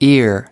us_phonetics_sound_ear_2023feb.mp3